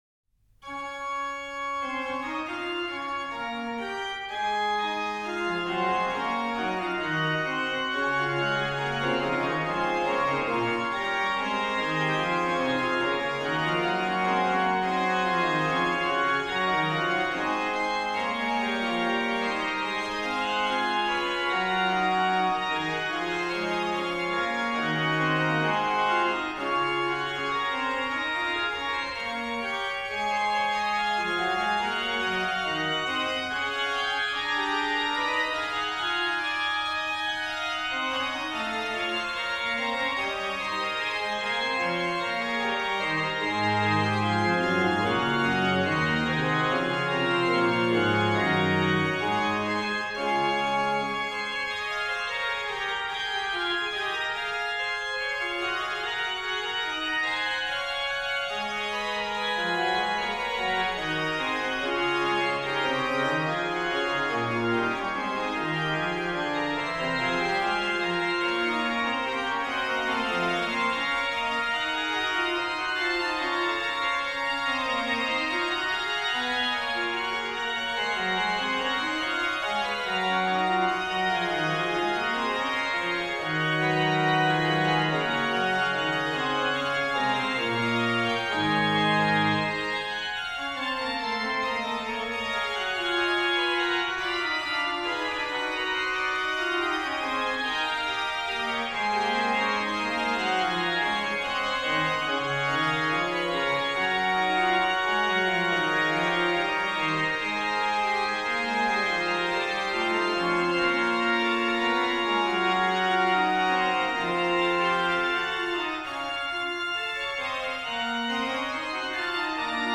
2. Fuga
PED: Pr16, Oct8, Oct4, Mix, Pos16, Tr8
m. 65: Ped: +32Pos